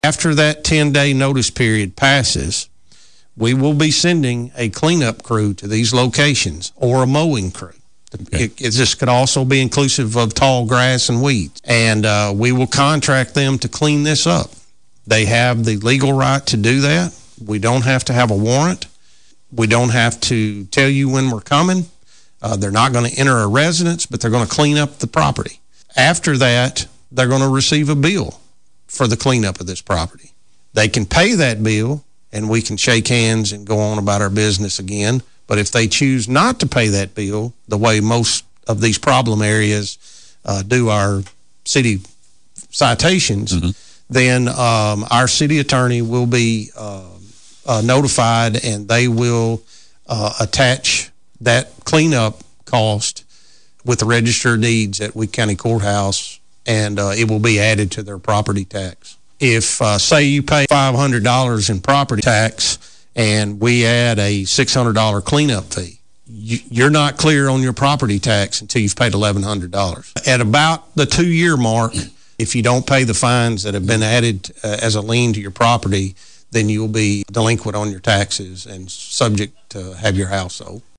Chief Fuqua says a violator has 10 days to clean up the property. If not cleaned up, police will move to a more aggressive manner to begin the clean up.